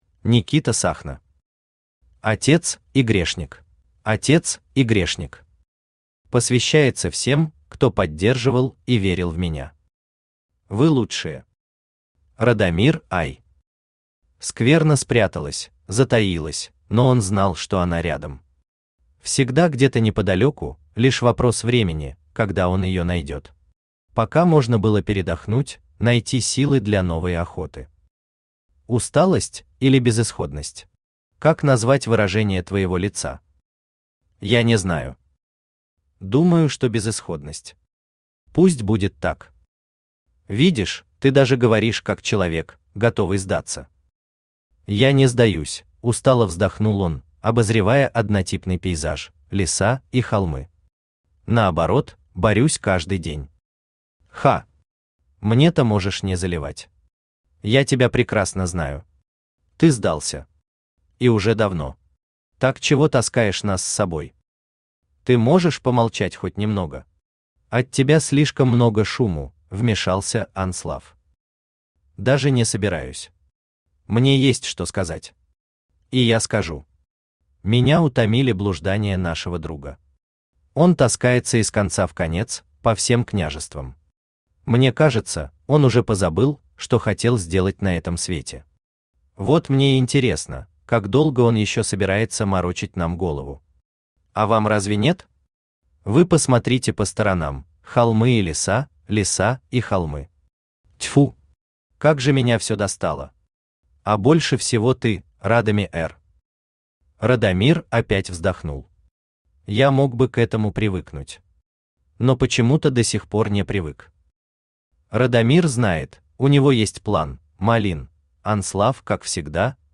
Аудиокнига Отец и грешник | Библиотека аудиокниг
Aудиокнига Отец и грешник Автор Никита Сахно Читает аудиокнигу Авточтец ЛитРес.